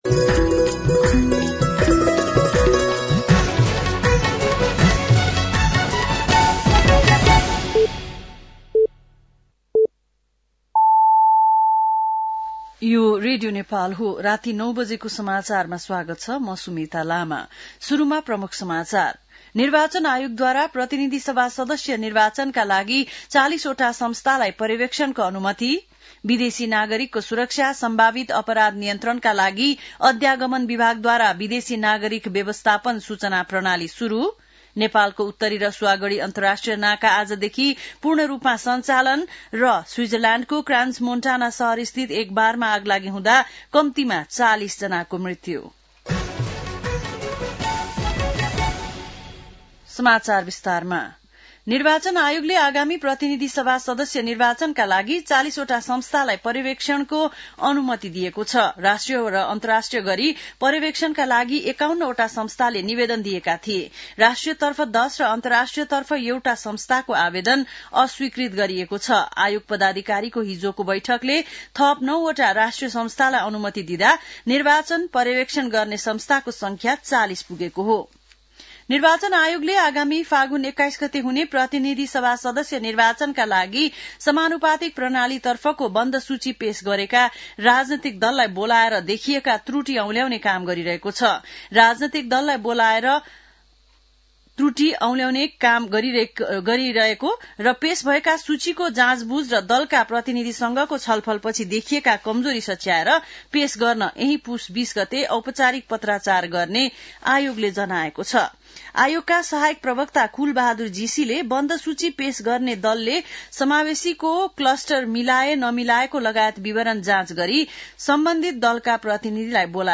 बेलुकी ९ बजेको नेपाली समाचार : १७ पुष , २०८२
9-PM-Nepali-NEWS-09-17.mp3